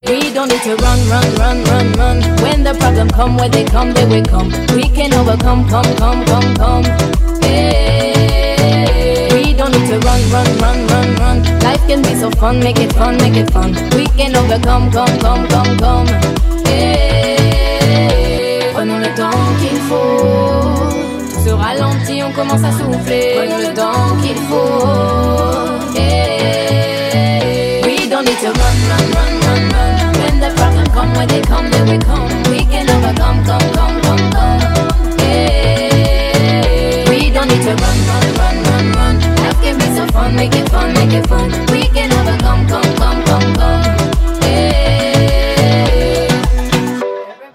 • Качество: 320, Stereo
ритмичные
dance
vocal